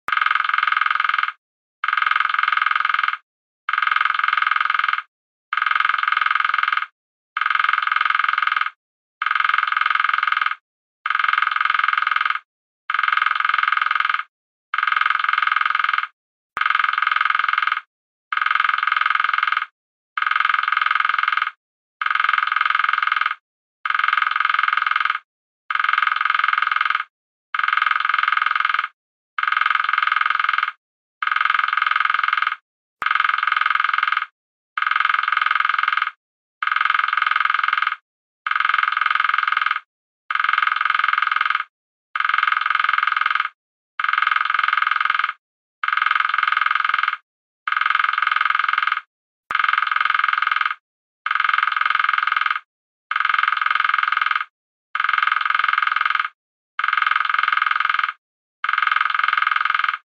دانلود صدای نوک زدن دارکوب از ساعد نیوز با لینک مستقیم و کیفیت بالا
جلوه های صوتی